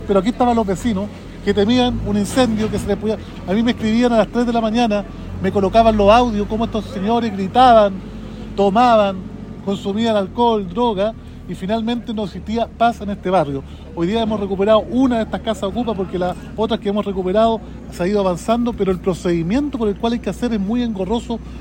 Al lugar llegó el alcalde de Temuco, Roberto Neira, quien relató lo complejo que es el trámite para obtener la autorización que permite a la fuerza pública desalojar una casa ocupa.